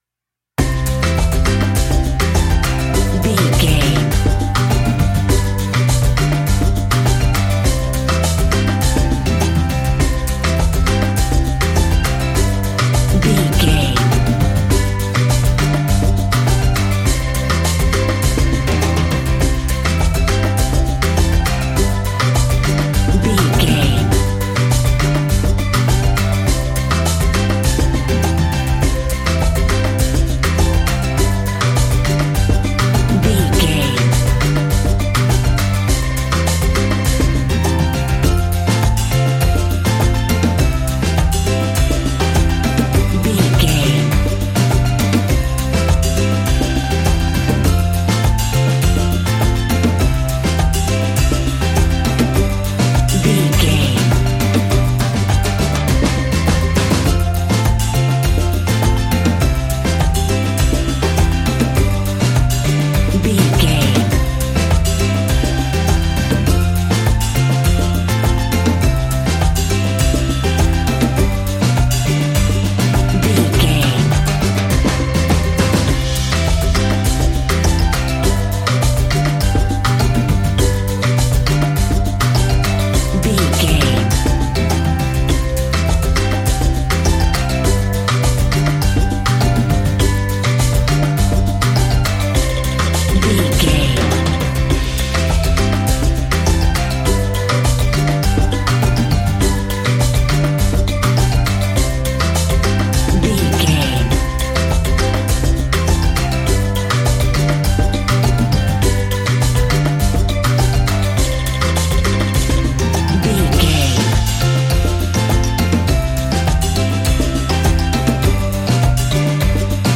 Ionian/Major
cheerful/happy
mellow
drums
electric guitar
percussion
horns
electric organ